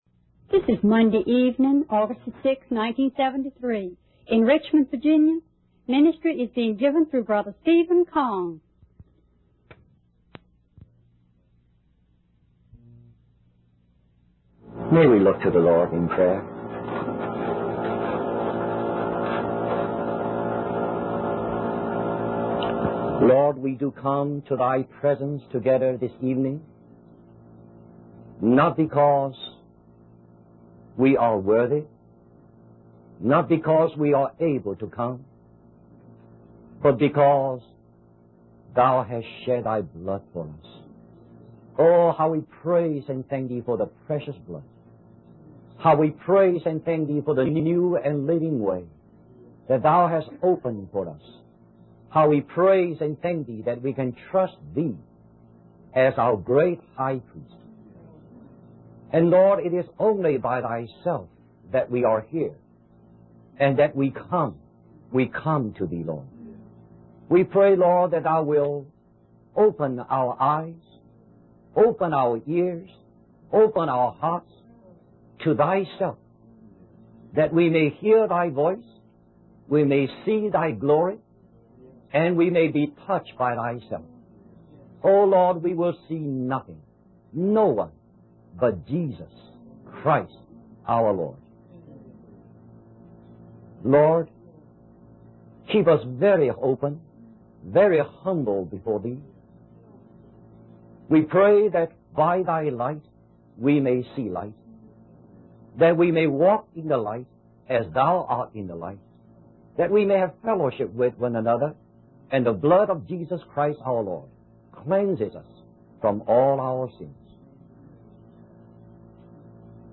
In this sermon, the speaker emphasizes the destructive nature of divisions among God's people in the church.